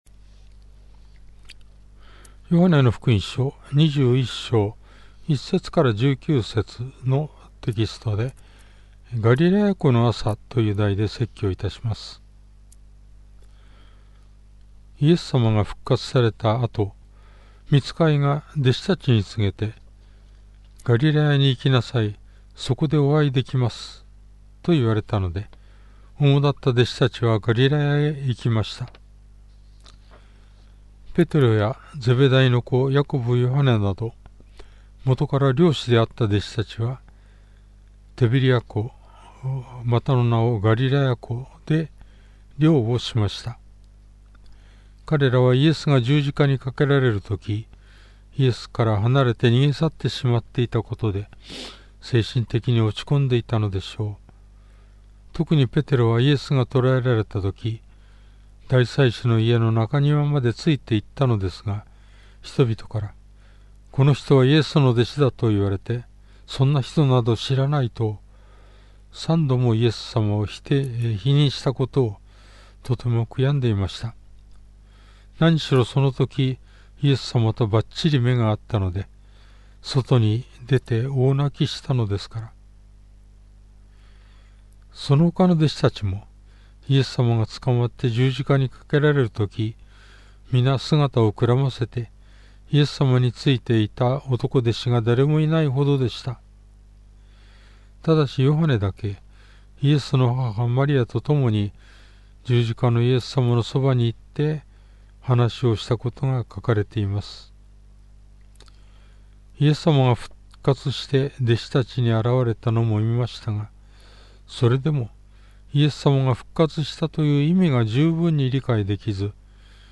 Sermon
2021年 4月11日 主日礼拝 説教 ヨハネの福音書21章 1～19節 21:1 その後、イエスはティベリア湖畔で、再び弟子たちにご自分を現された。
もし自動的に音声が流れない 場合、ここをクリック 当日の説教 <準備中> 事前録音分 説教要旨 イエス様が復活された後、ガリラヤで弟子たちに 現れたことがヨハネ２１章にある。